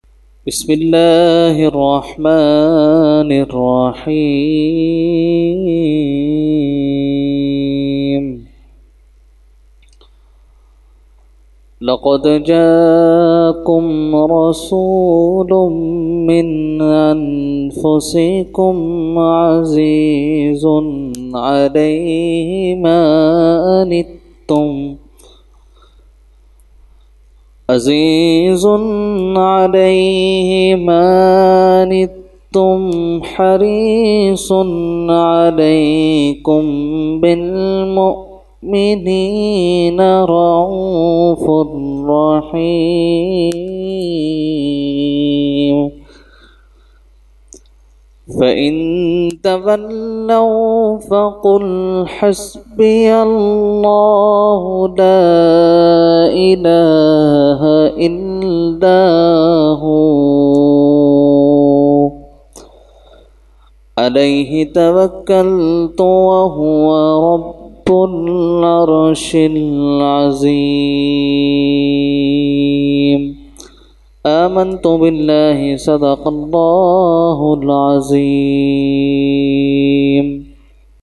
Qirat – Khatam Hizbul Bahr 2020 – Dargah Alia Ashrafia Karachi Pakistan